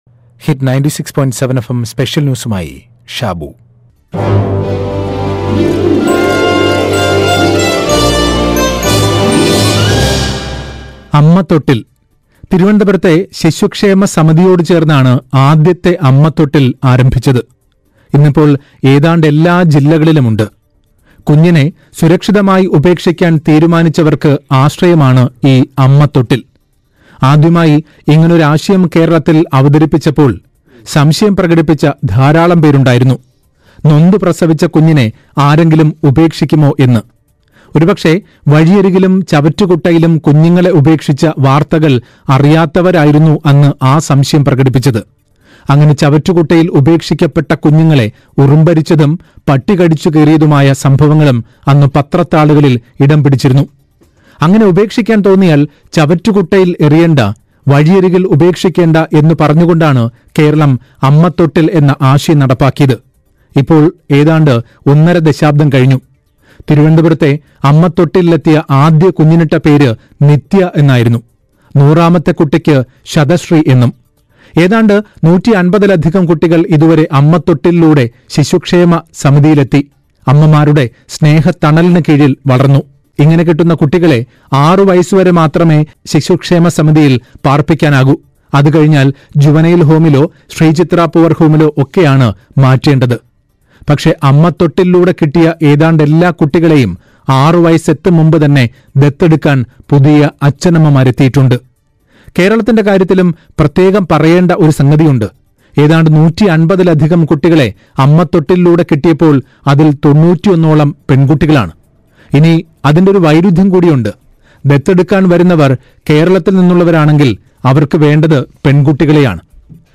സ്‌പെഷ്യൽ ന്യൂസ്